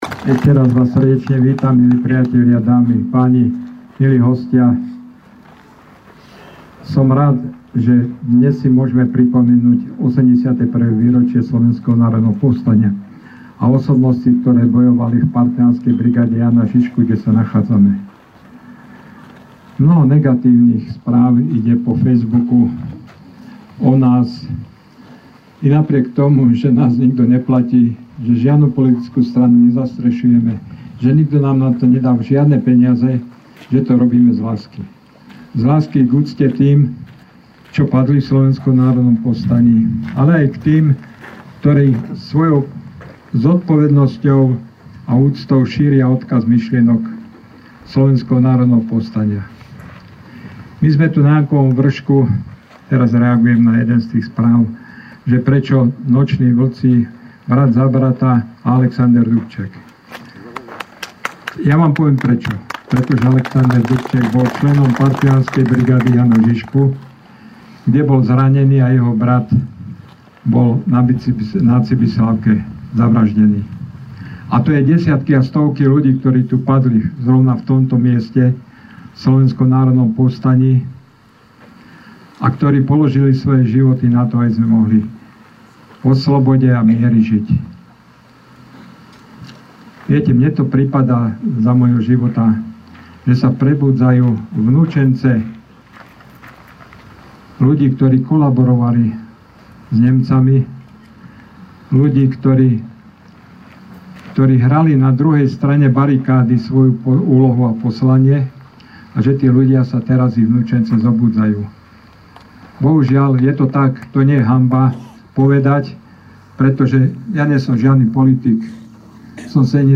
Príhovor